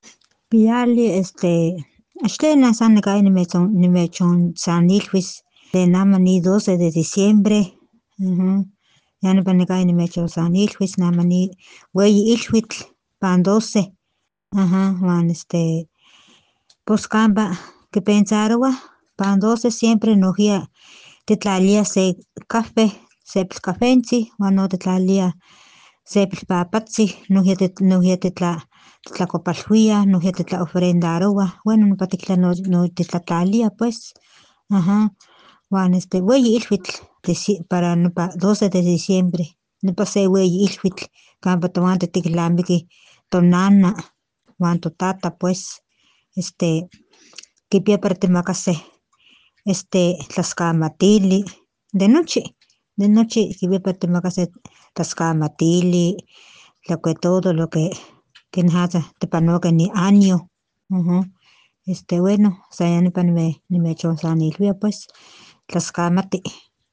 Ofrenda-Nahuatl-de-Postectitla-Chicontepec..mp3